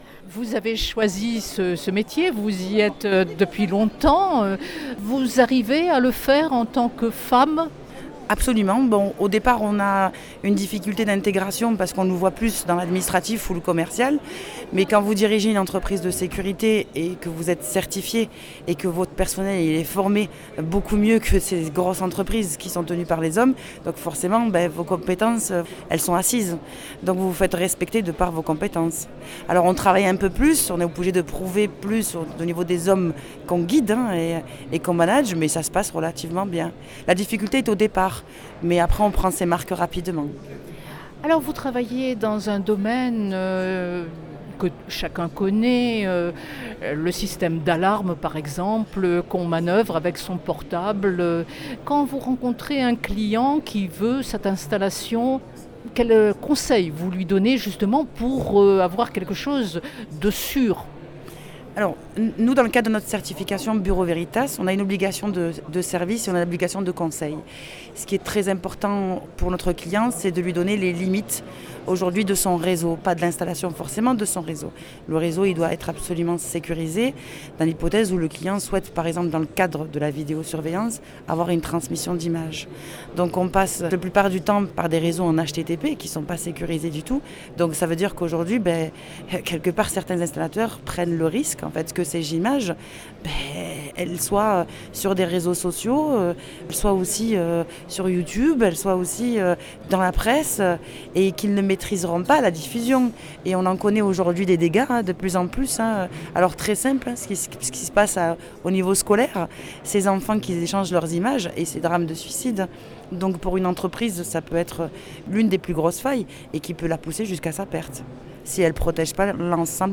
AccesSecurity, le Salon Méditerranéen de la sécurité globale, dont la cybersécurité, a ouvert ses portes, ce mercredi 29 mars pour 3 journées au Parc Chanot à Marseille.